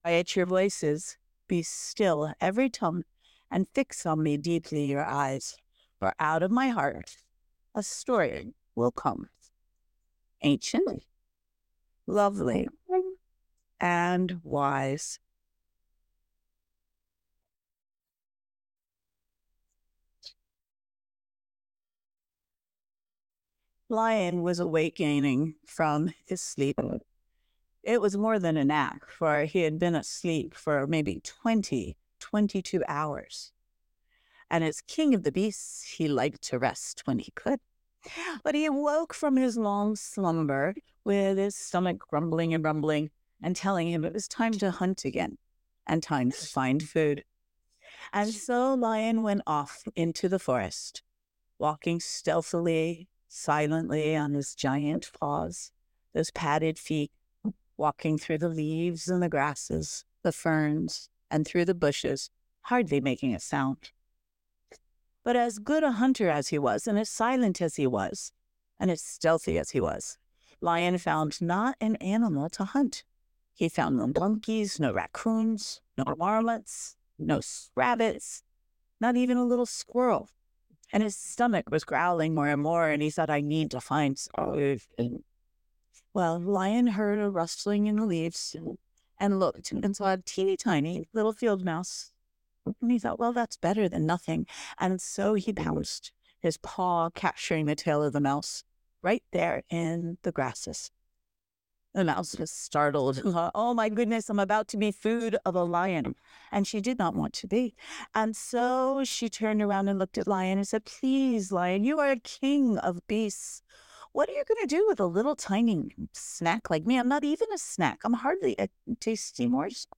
Storytelling: The Lion and the Mouse